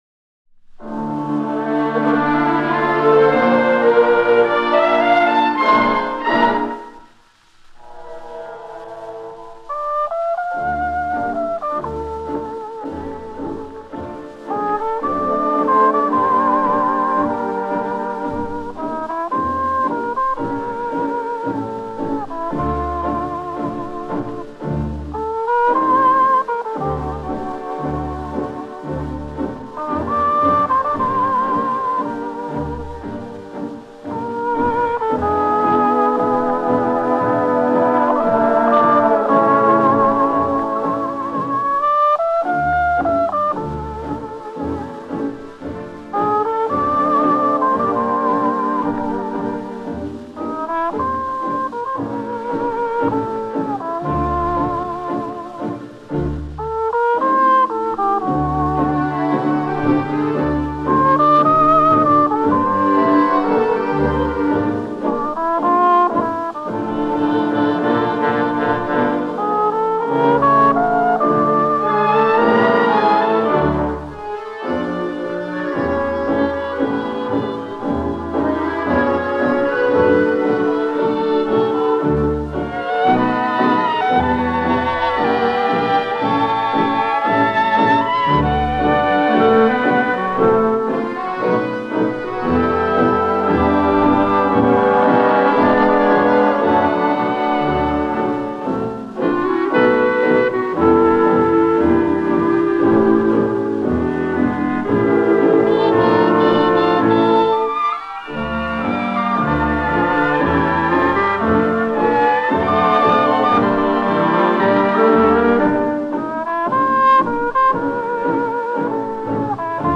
Известнейший трубач и замечательная композиция